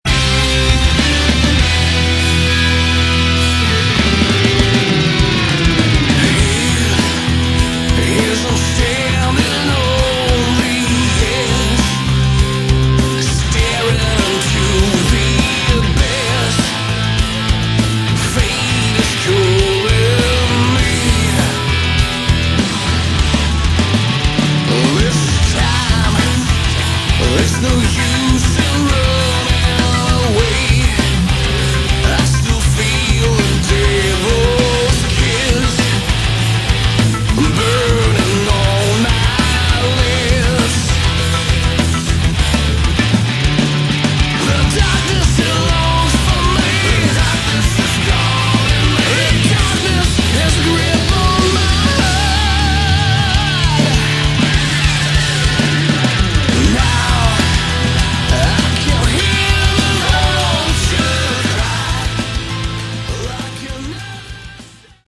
Category: Hard Rock
Vox
Guitars, Bass, Keyboards
Drums